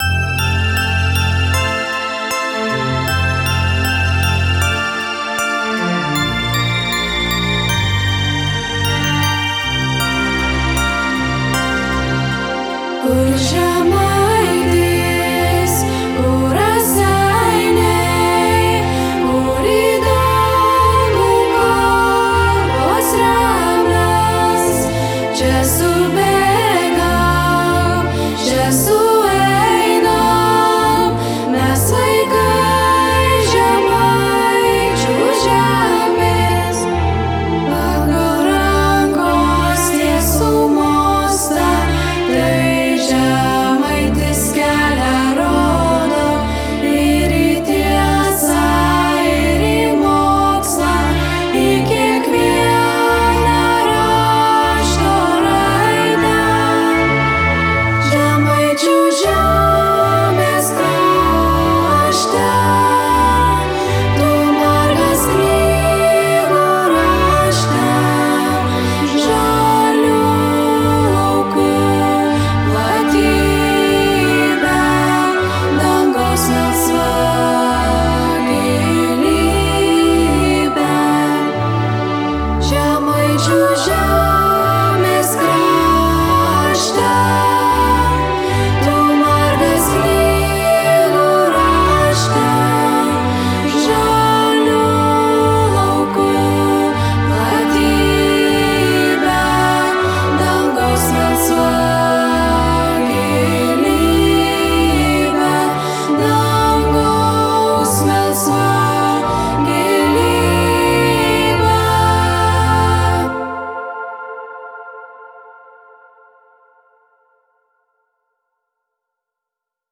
Raseiniu gimnazijos himnas (su instrumentais)
Raseiniu gimnazijos Himnas (su instrumentais).wav